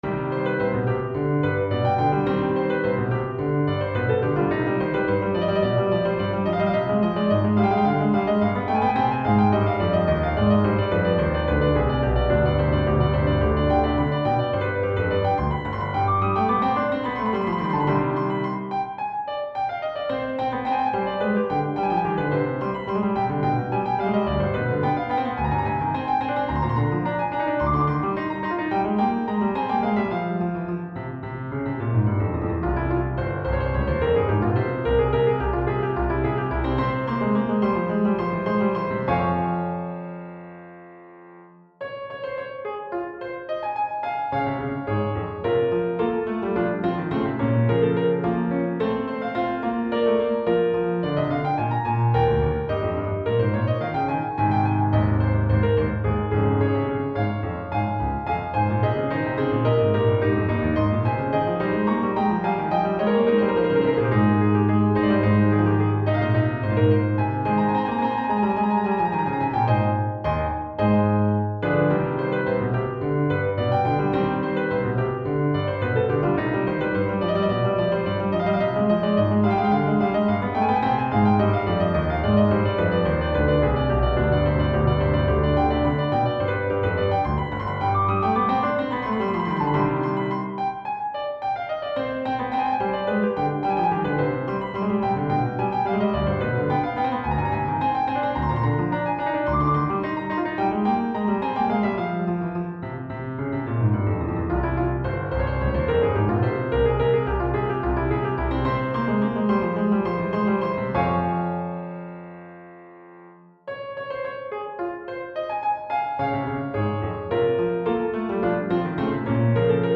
This is a set of three pieces, though composed individually, that I put together as a single opus number, each reflecting an attempt to compose within a specific style. The first is a "sonata" akin to the single movement works of Domenico Scarlatti.